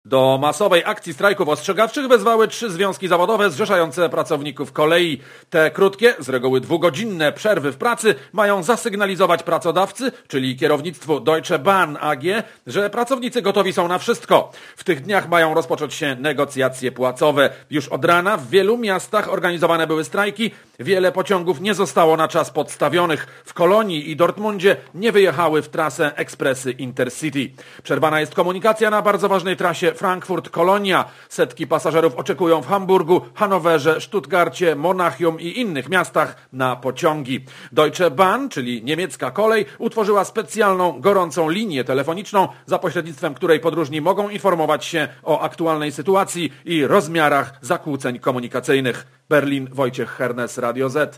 Korespondecja z Niemiec (400Kb)